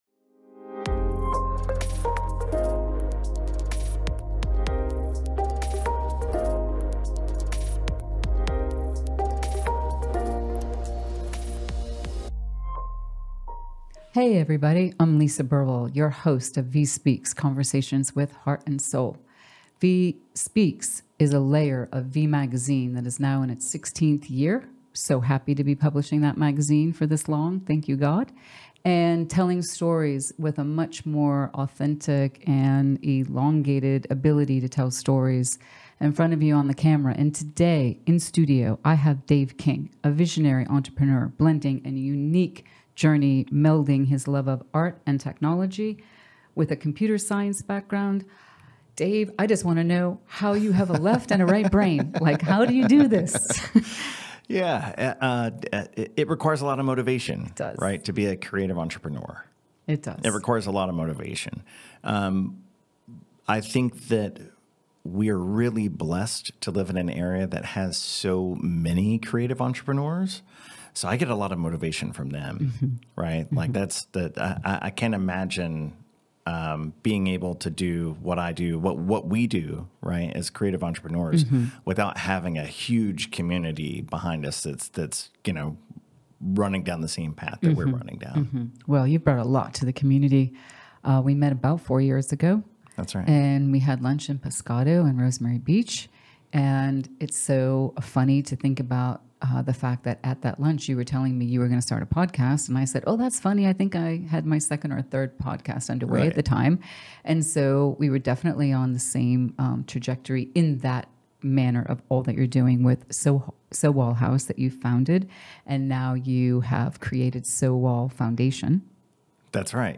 Tune in for an inspiring conversation about art, purpose, and the power of supporting bold, game-changing projects.